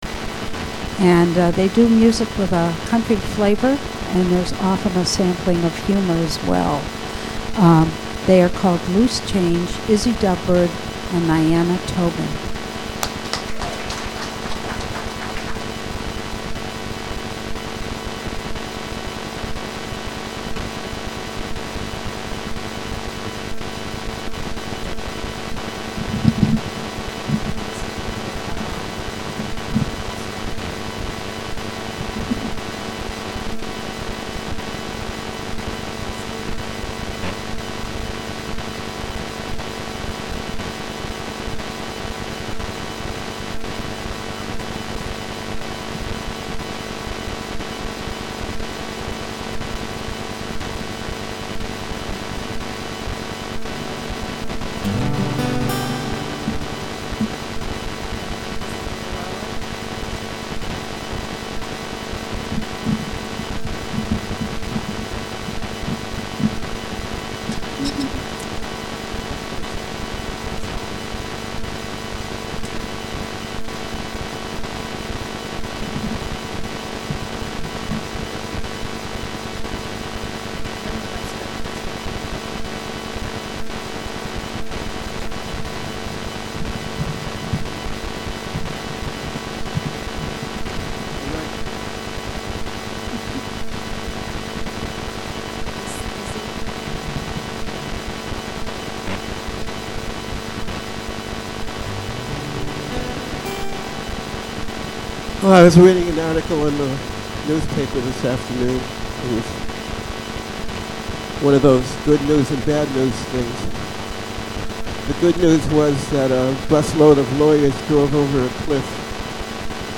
Files with a title starting raw or Untitled have only been track-level volume adjusted and are not joined, clipped, equalized nor edited.
Apologies, but there were some technical problems with tracks 02 through 08, 14, 16, and 17, and the recording quality is not as good  as usual.